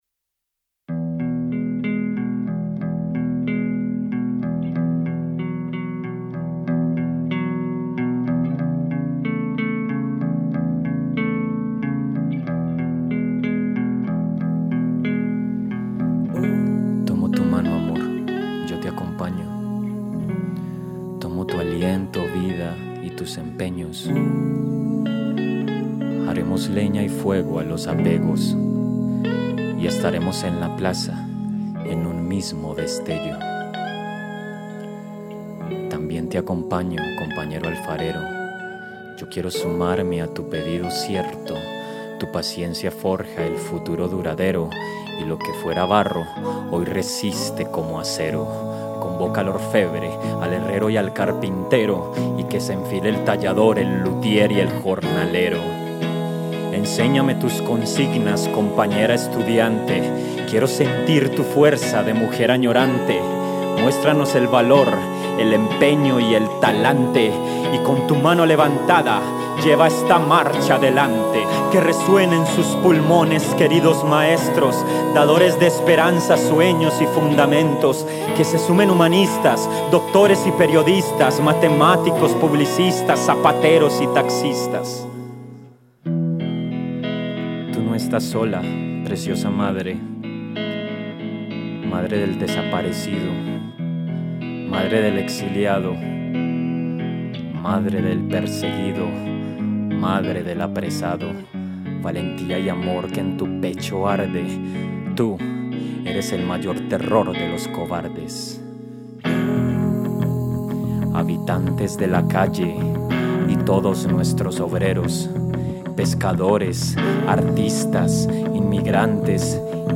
bateria, voz y compositor.
bajo, voz y compositor.
guitarra, voz y compositor.